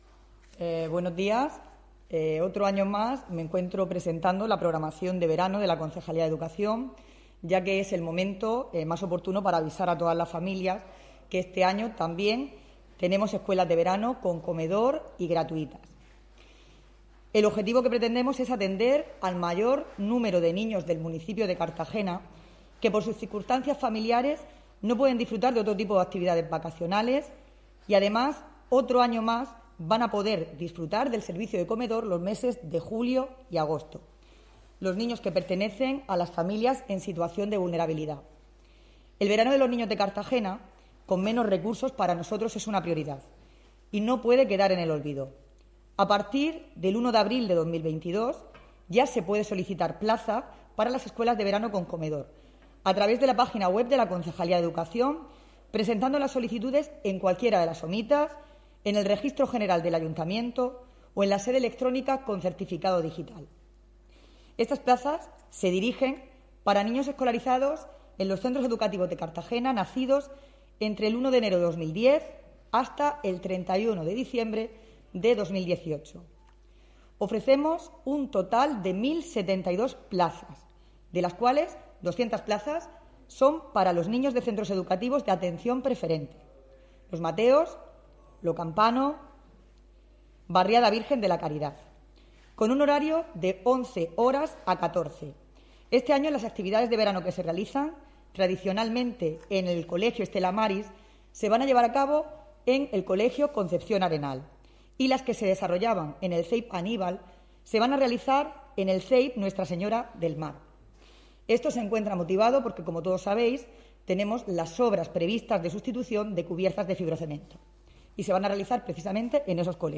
La concejala de Educación ha anunciado que las solicitudes se podrán realizar de forma telemática y mantiene las 1.072 plazas del año pasado